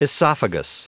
Prononciation du mot esophagus en anglais (fichier audio)
esophagus.wav